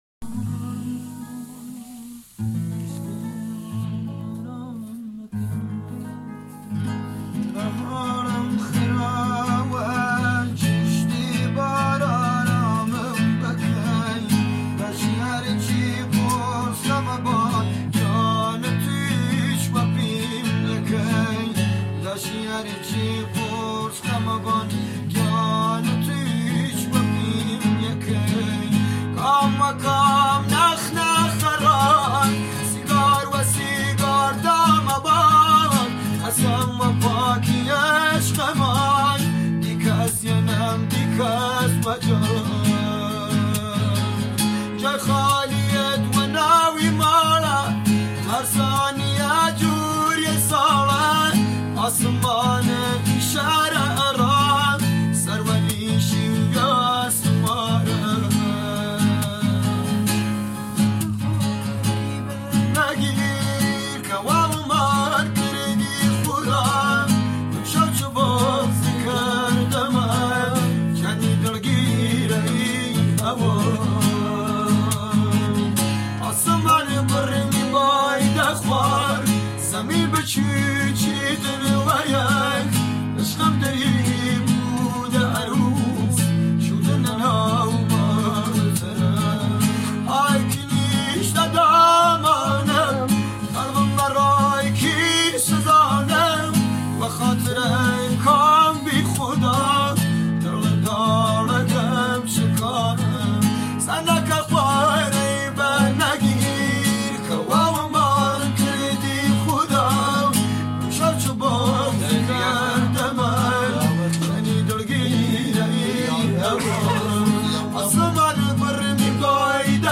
آهنگ کردی کرمانشاهی با گیتار